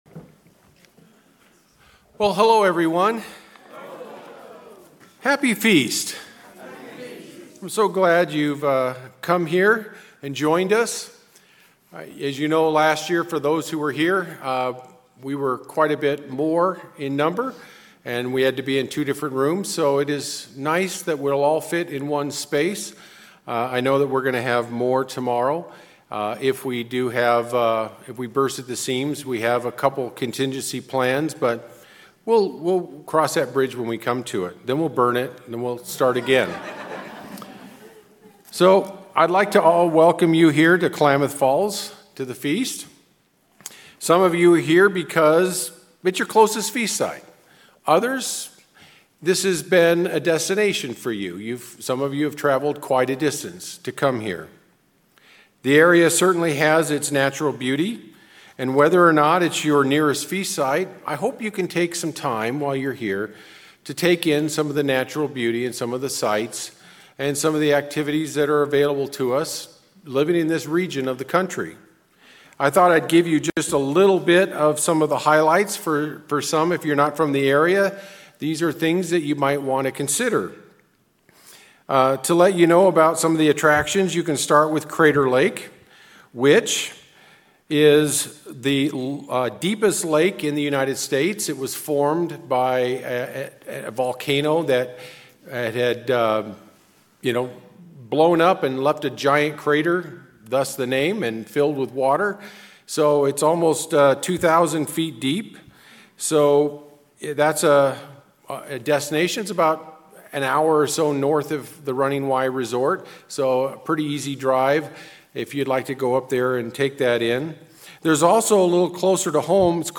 Given in Klamath Falls, Oregon